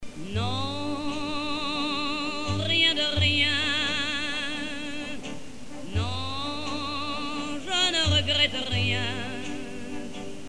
Llevaba unos audífonos y cantaba con una voz rasposa “